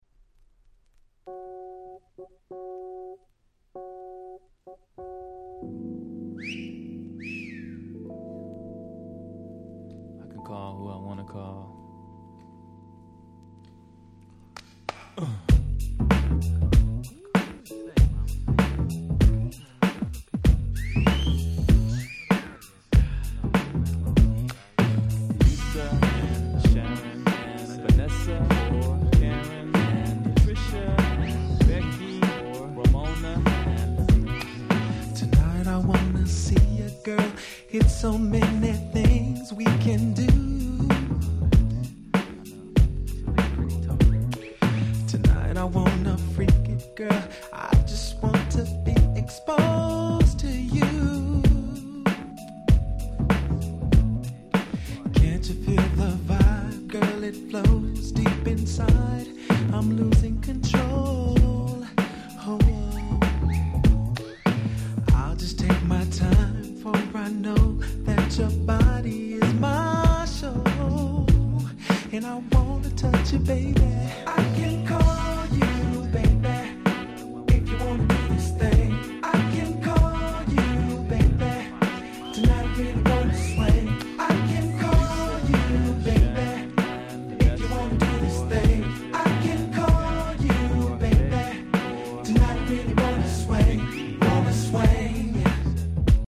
派手過ぎず、かと言ってしっかりフロア映えもする超Groovyな1曲！
Radio Edit